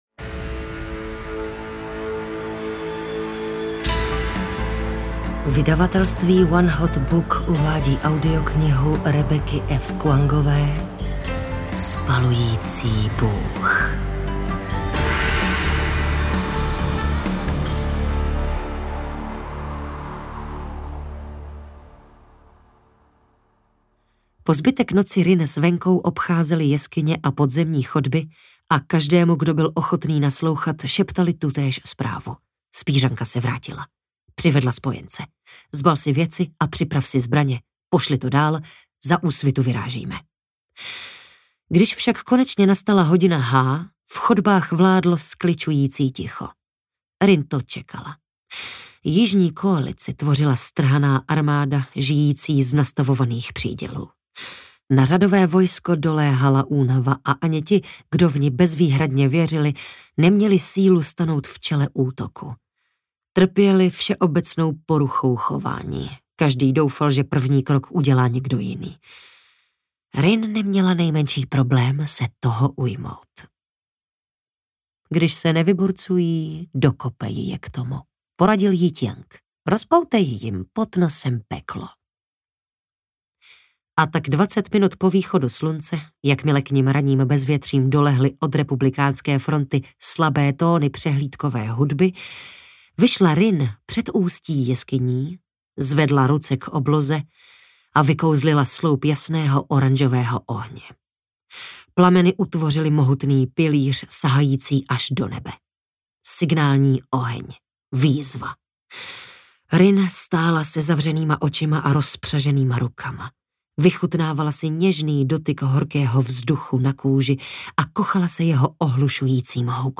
Spalující bůh audiokniha
Ukázka z knihy
• InterpretTereza Dočkalová